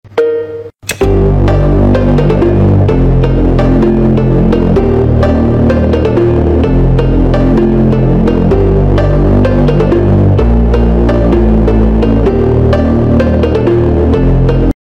фонк